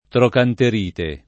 trocanterite [ trokanter & te ]